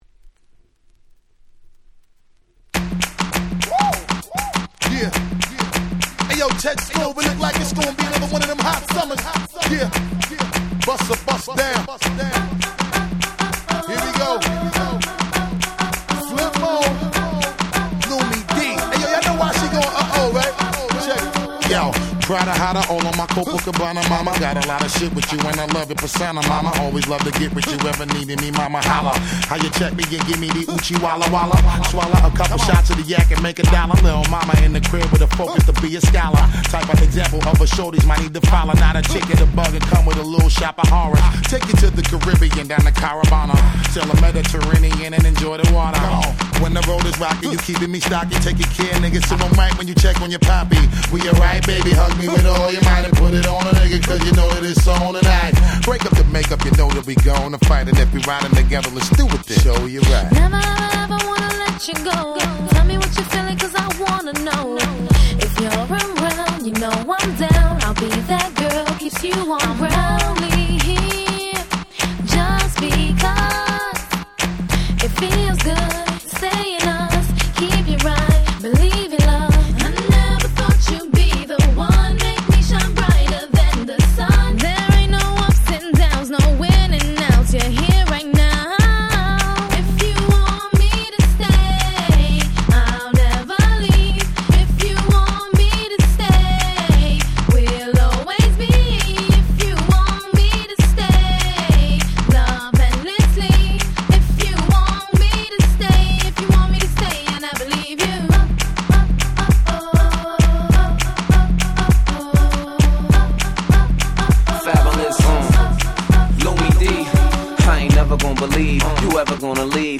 03’ Super Hit R&B !!